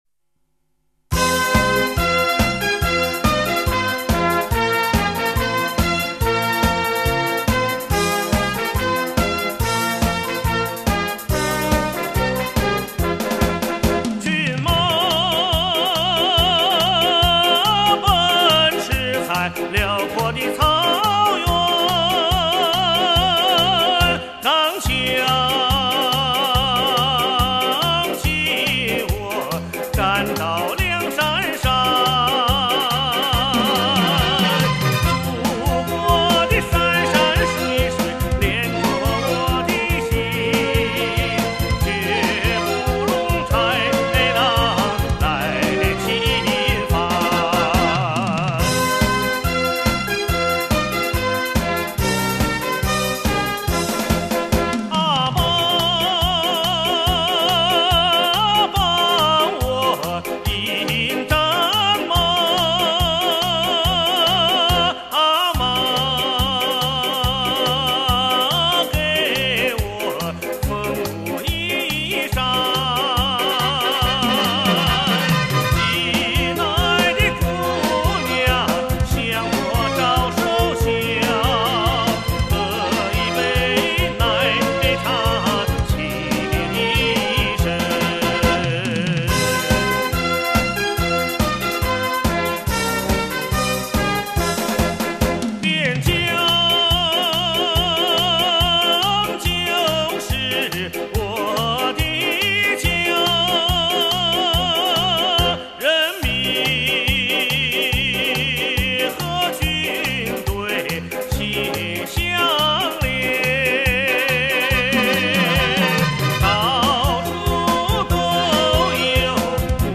试听低音质128K